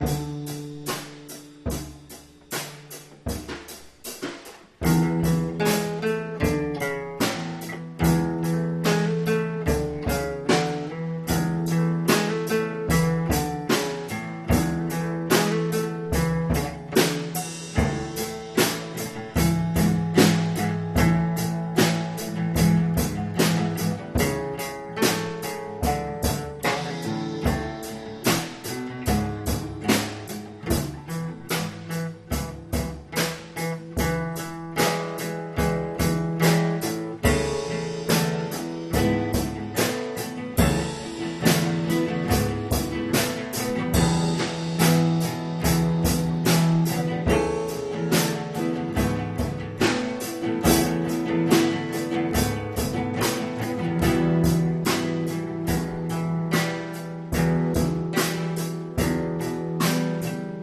This is the first clip from our first time playing with this new drum set.
There is a 1 bar loop in this one I could see using for something else.
STE-006_newdrums_mix.mp3